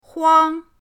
huang1.mp3